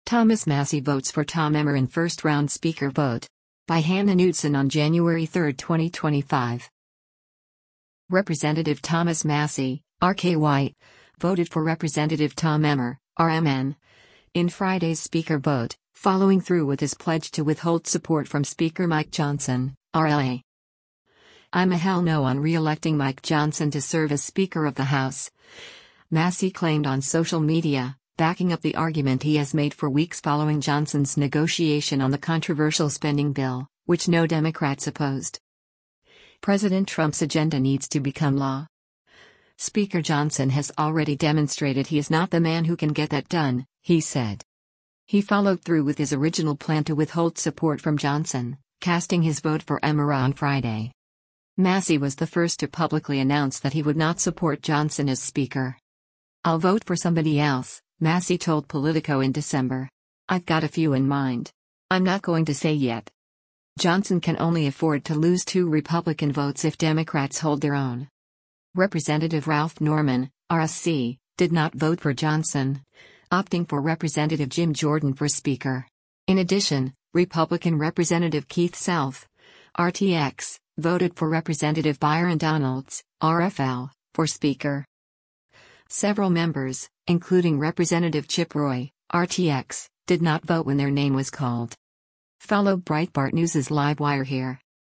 Thomas Massie Votes for Tom Emmer in First-Round Speaker Vote